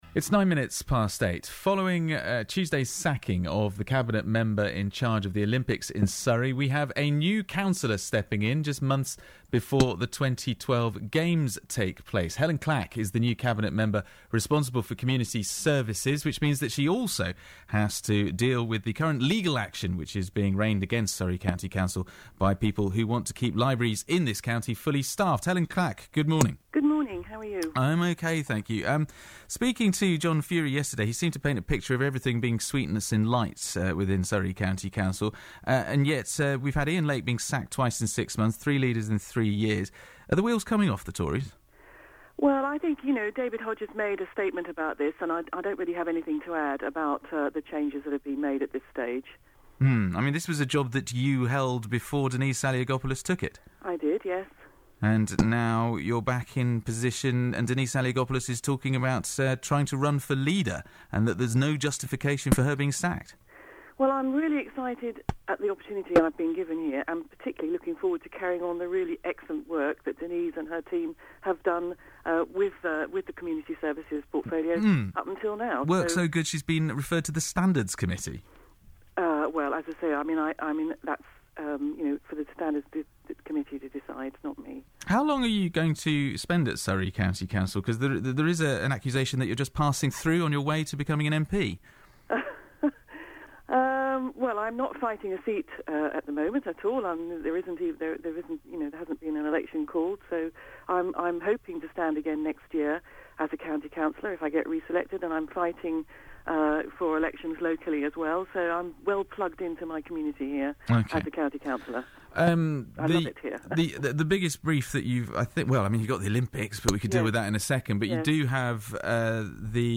Cabinet members interviewed by BBC Surrey
Helyn Clack – Cabinet Member for Community Services and the 2012 Games.